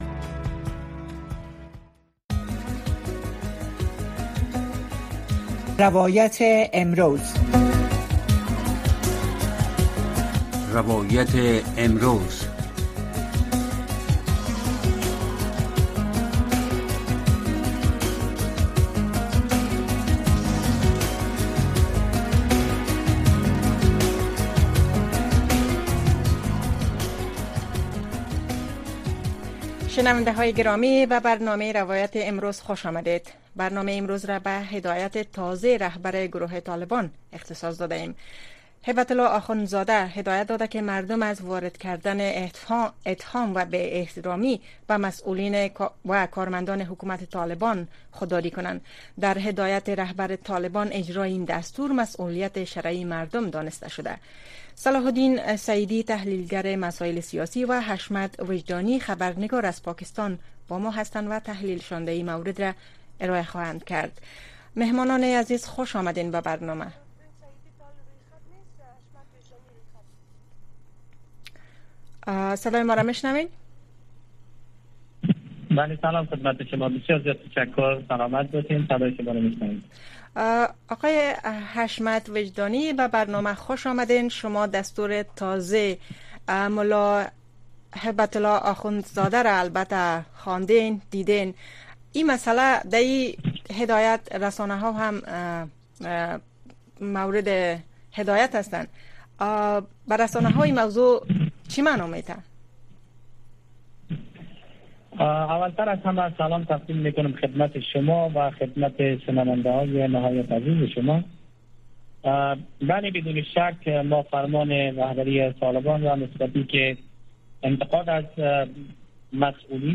در برنامۀ روایت امروز شرح وضعیت در افغانستان را از زبان شهروندان و شرکت کنندگان این برنامه می‌شنوید. این برنامه هر شب از ساعت ٩:۳۰ تا ۱۰:۰۰ شب به گونۀ زنده صدای شما را پخش می‌کند.